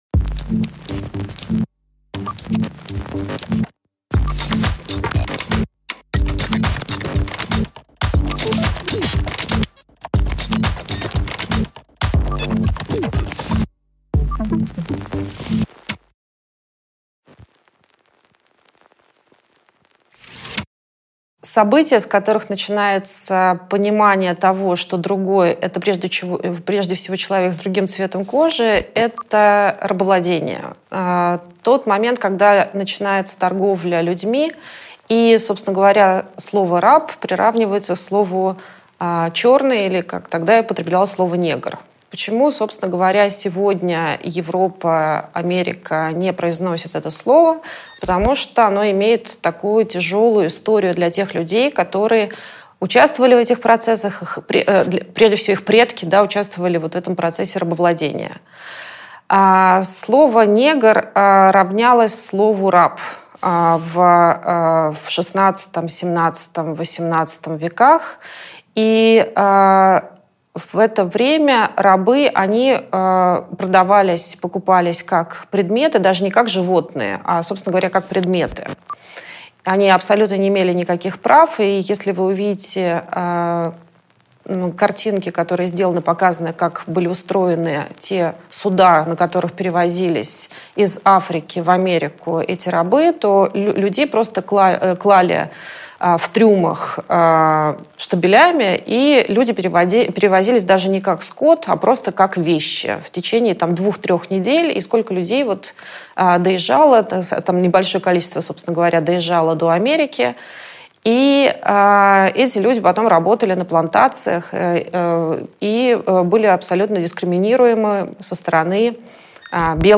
Аудиокнига Границы в движении | Библиотека аудиокниг
Прослушать и бесплатно скачать фрагмент аудиокниги